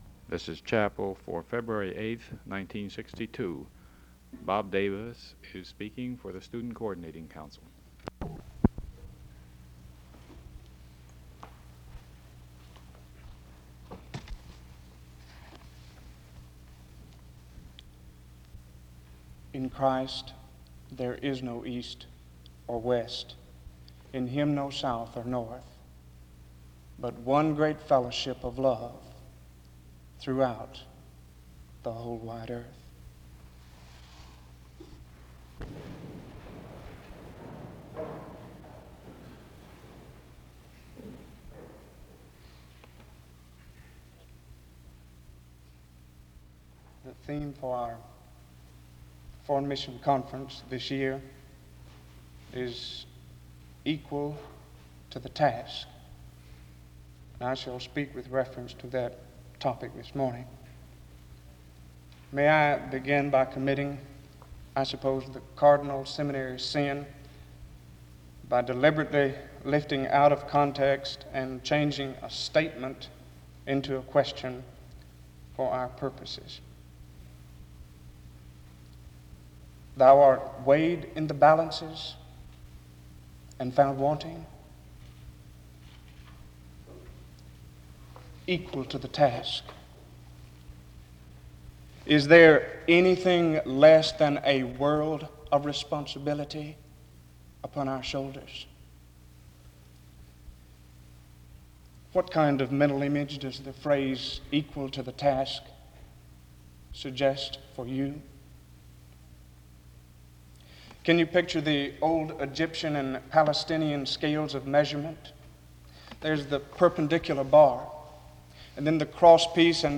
The service starts with a spoken word from 0:19-0:34.
He preaches about how the church needs to step up in the world and to reach the material and spiritual needs of all those around them. The service was organized by the Student Coordinating Council.